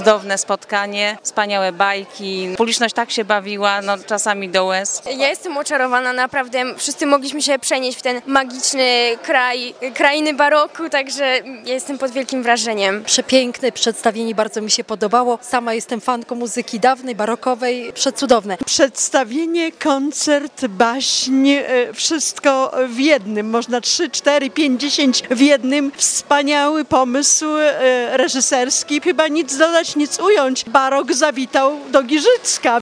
– Warto było uczestniczyć w tym niezwykłym przedstawieniu  i  zobaczyć oraz usłyszeć coś, czego jeszcze w Giżycku nie było – powtarzali zachwyceni spektaklem mieszkańcy.
wypowiedzi-barok.mp3